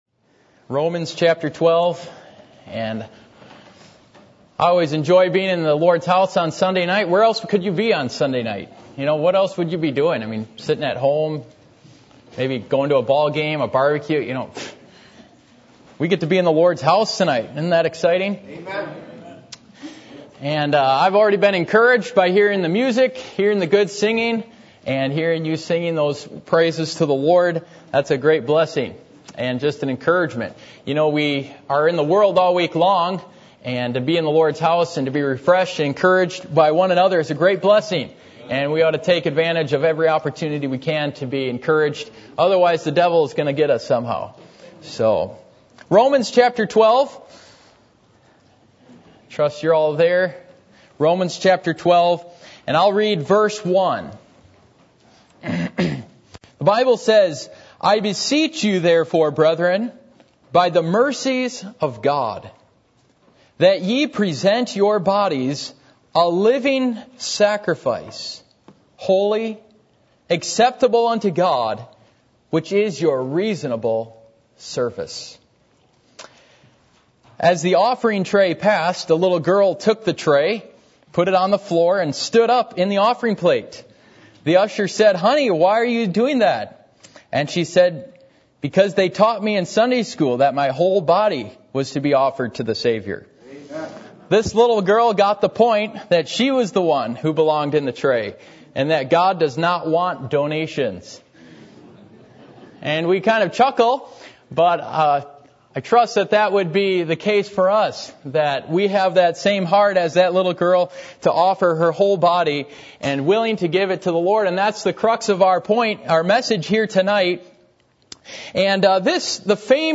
Passage: Romans 12:1, Ephesians 2:1-4 Service Type: Sunday Evening